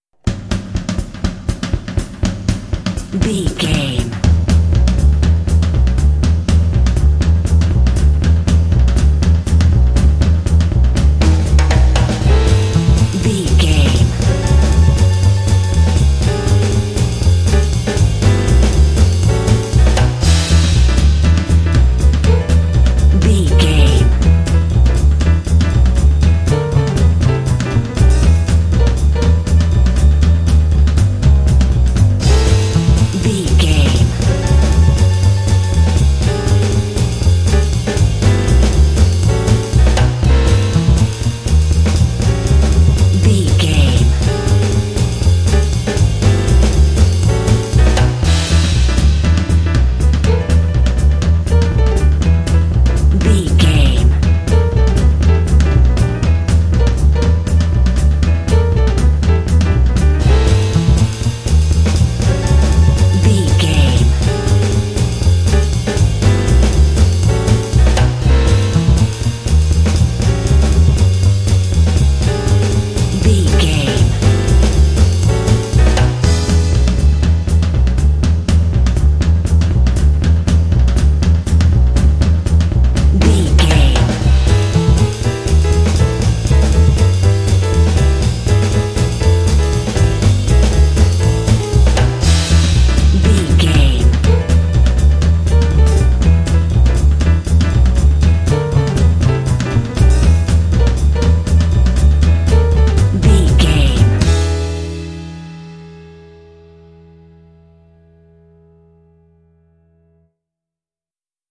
Uplifting
Aeolian/Minor
Fast
energetic
lively
cheerful/happy
drums
double bass
piano
big band
jazz